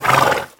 SFX_caballoBufido1.wav